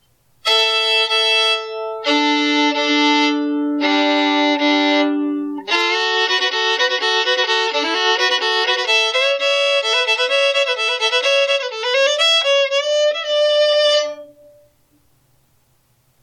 I would classify this one as very loud in volume with bright and clear tone quality.